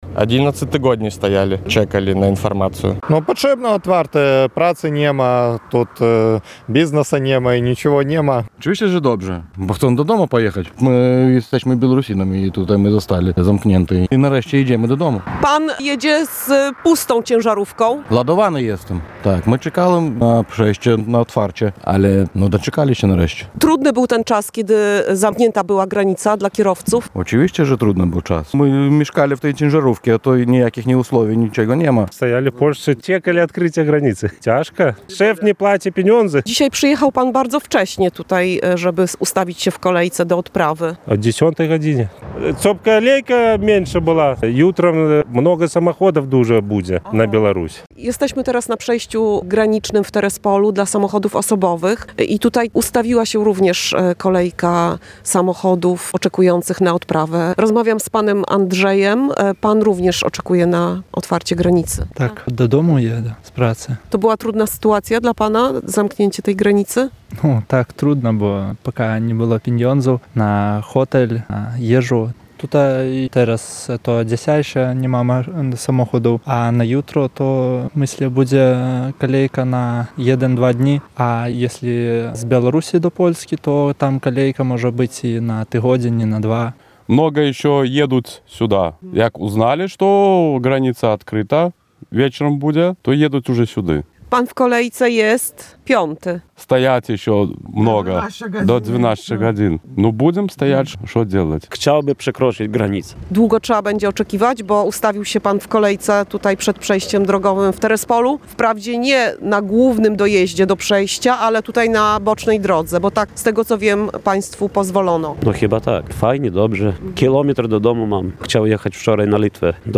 – Z niecierpliwością oczekiwaliśmy na otwarcie granicy – mówią kierowcy tirów oczekujący na drodze krajowej nr 2.